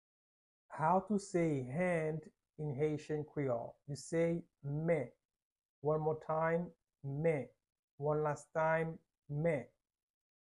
How to say "Hand" in Haitian Creole - "Men" pronunciation by a native Haitian Teacher
“Men” Pronunciation in Haitian Creole by a native Haitian can be heard in the audio here or in the video below:
How-to-say-Hand-in-Haitian-Creole-Men-pronunciation-by-a-native-Haitian-Teacher.mp3